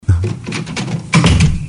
door
Tags: funny movie quote sound effect